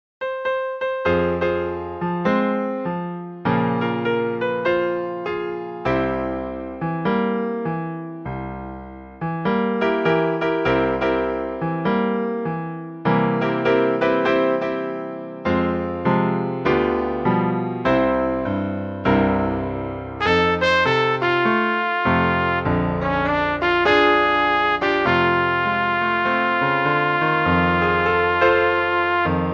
Swingová přednesová skladba pro trubku